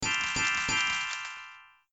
threeSparkles.ogg